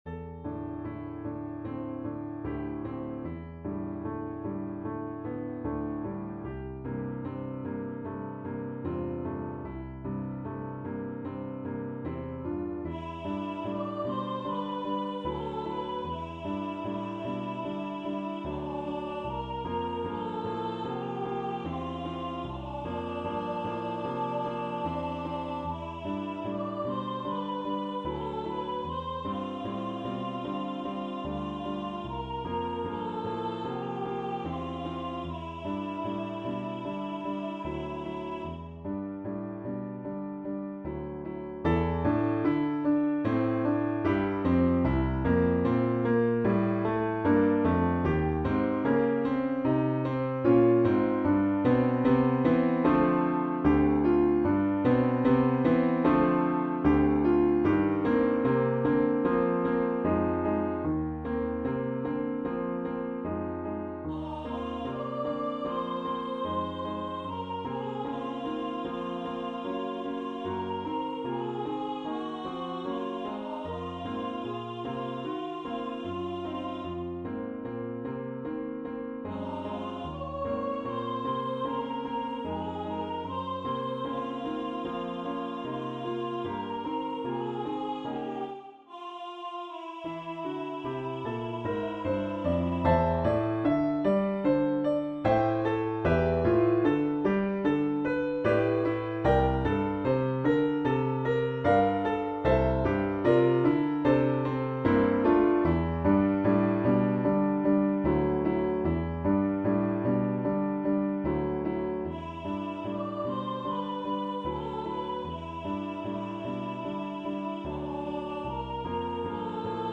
1v Voicing: Soprano solo Genre: Secular, Art song
Language: English Instruments: Keyboard